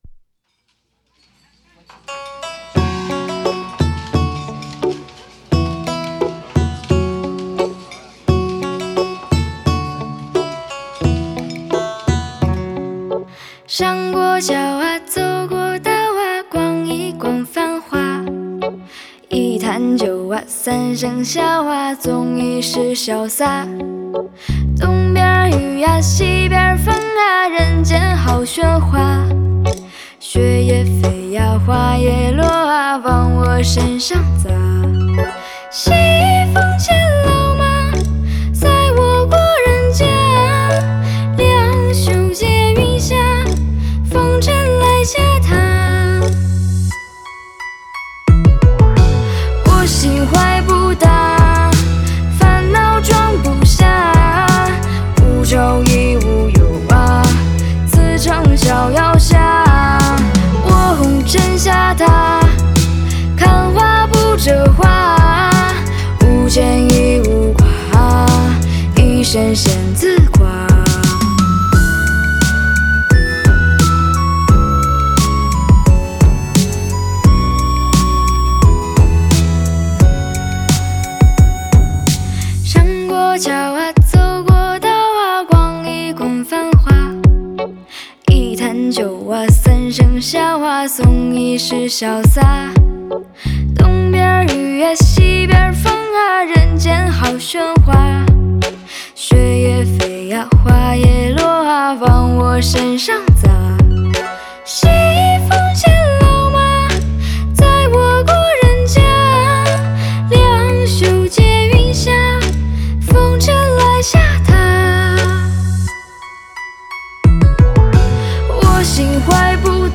在线试听为压缩音质节选
吉他